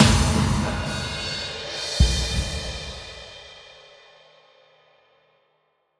Big Drum Hit 23.wav